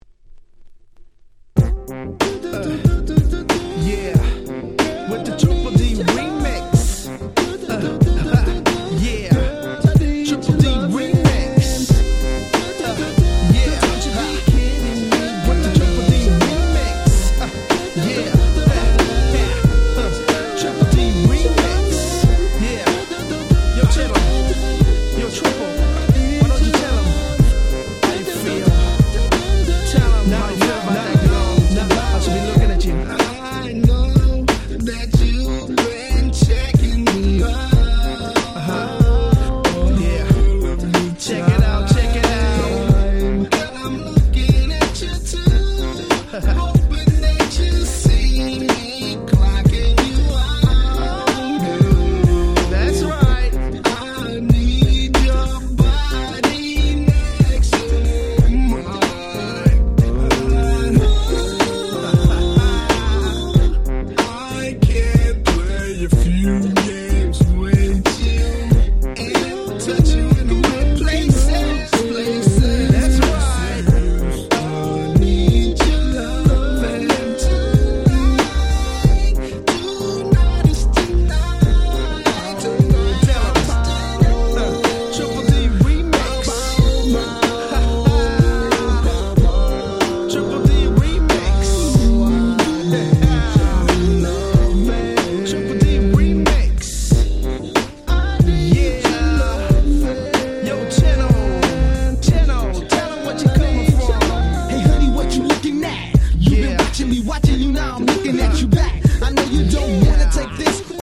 97' Very Nice Euro Rap / R&B !!
Talk Boxも超気持ち良いです！！
トリプルディー 90's R&B トークボックス プロモオンリー　West Coast G-Rap